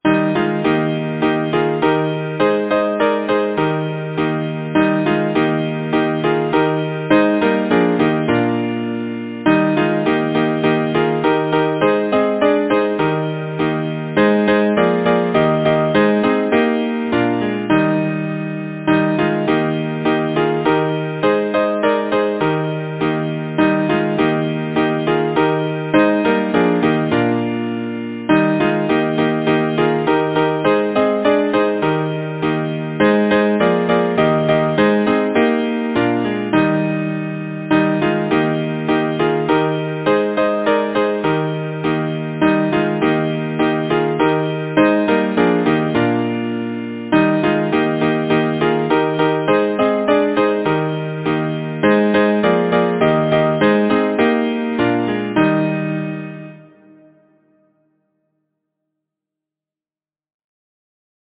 Title: The River’s Song Composer: Jacob Franklin King Lyricist: Number of voices: 4vv Voicing: SATB Genre: Secular, Partsong
Language: English Instruments: A cappella